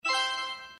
mic_grab_button_click.mp3